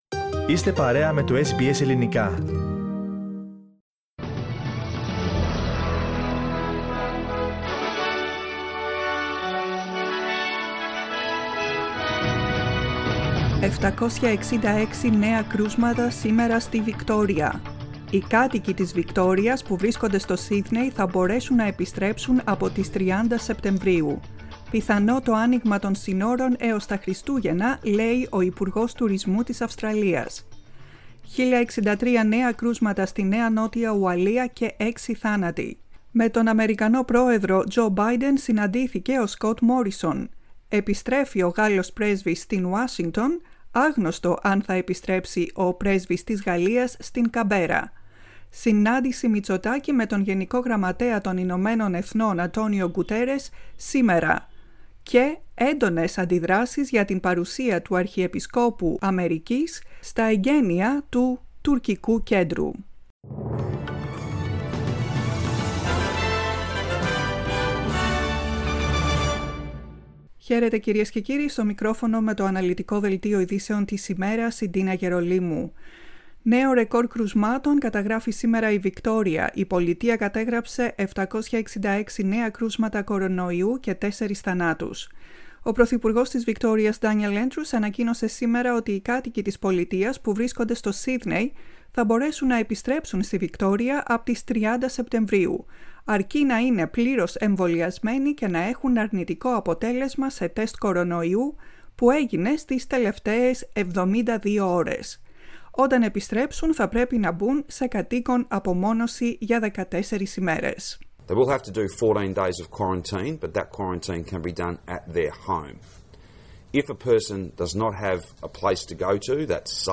Δελτίο ειδήσεων 23.09.21
Το κεντρικό δελτίο ειδήσεων του Ελληνικού Προγράμματος την Πέμπτη 23 Σεπτεμβρίου 2021.